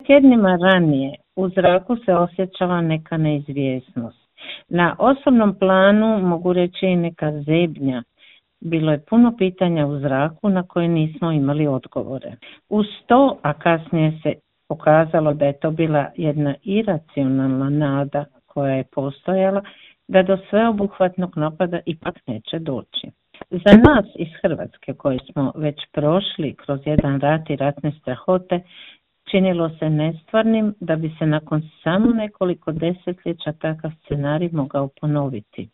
telefonskom Intervjuu Media servisa
hrvatsku veleposlanicu iz Kijeva Anicu Djamić